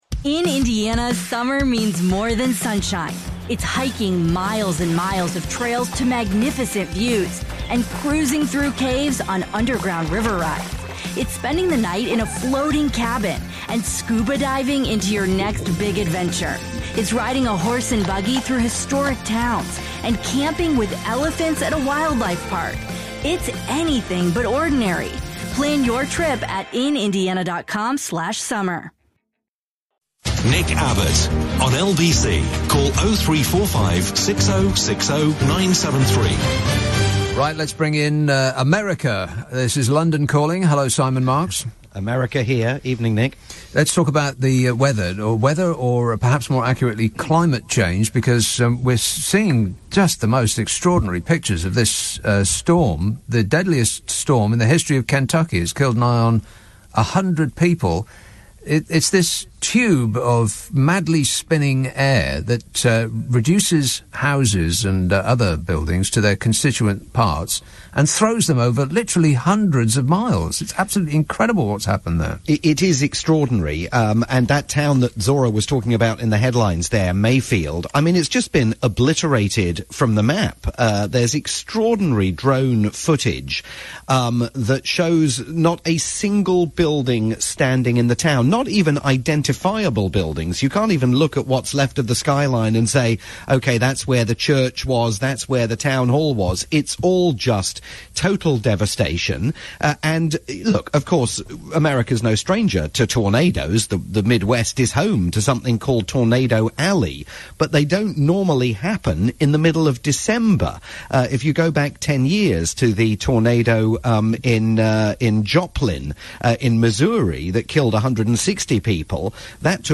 weekly roundup of US news